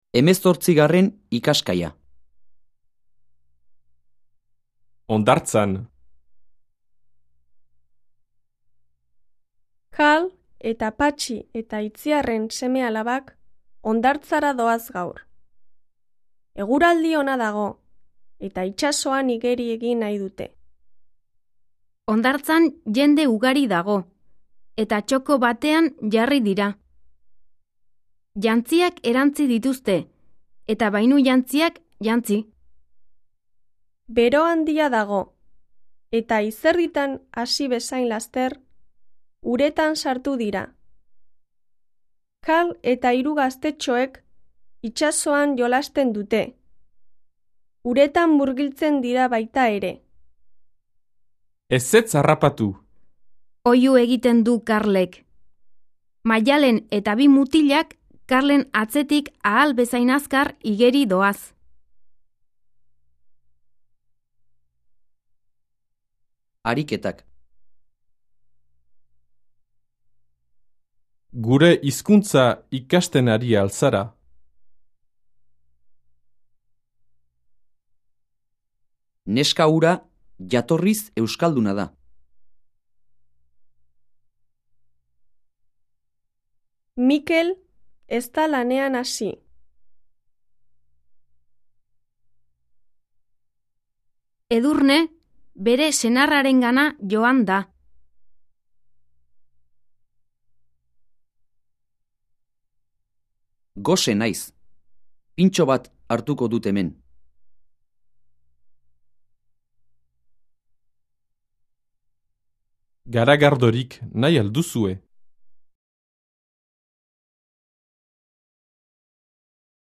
Диалог